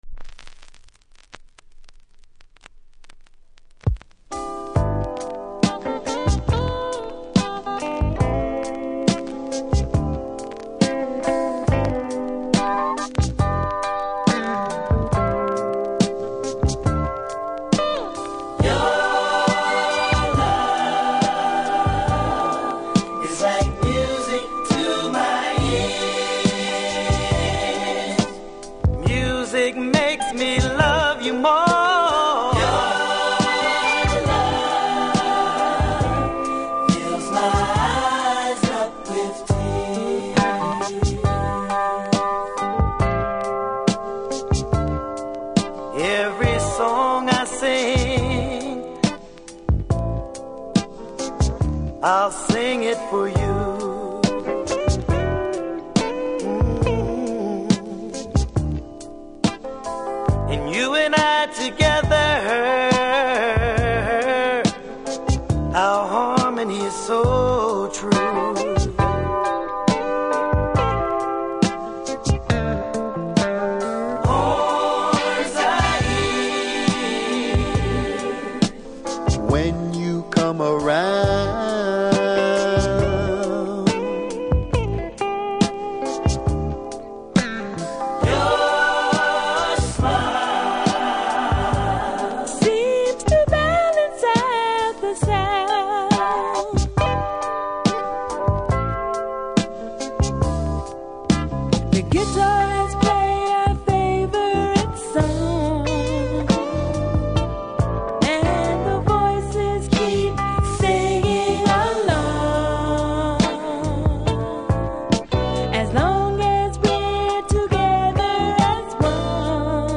Vinyl
間奏のサックス・ソロも良い感じ！
素晴らしい LOVERS ROCK に仕上がってます。